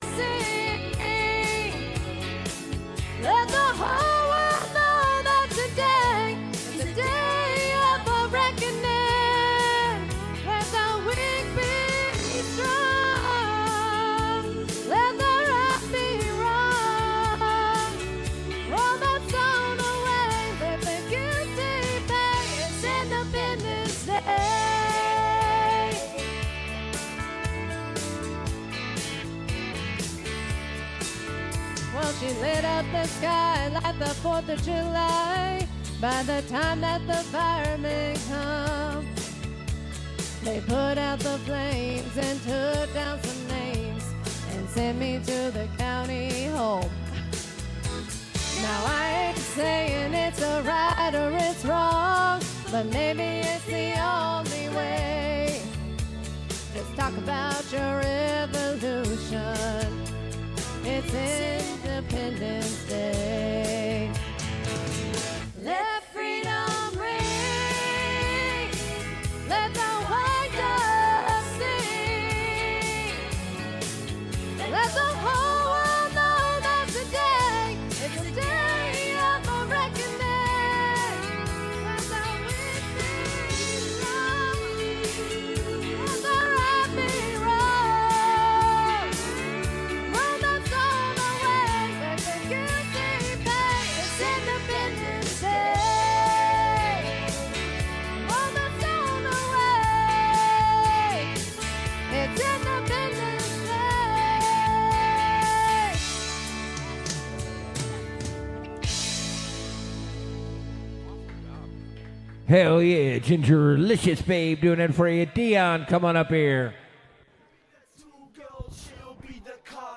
Live Wednesday through Saturday 9-1 with the Metro Best Karaoke with Mile High Karaoke on 16-Oct-25-22:03:39